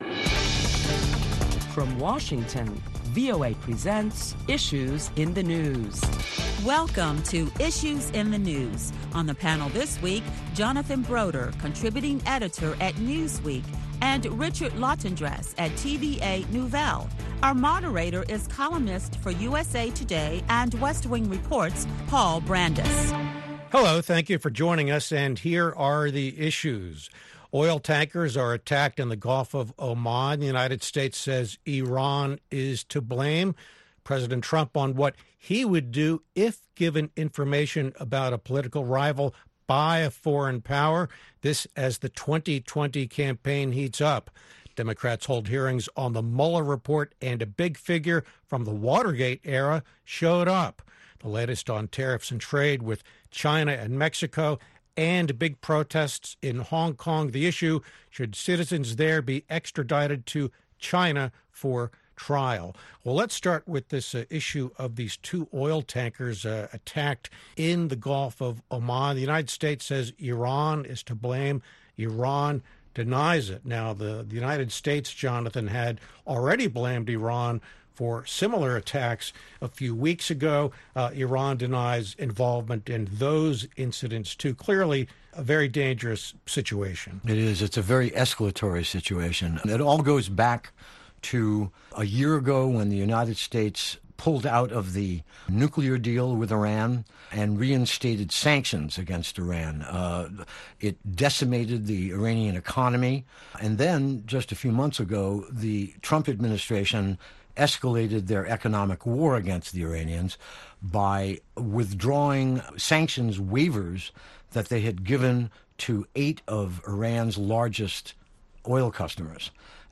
Listen to a panel of leading Washington journalists as they discuss the week's headlines beginning with the recent oil tanker attack in the Gulf of Oman, and why the U.S. says Iran is to blame.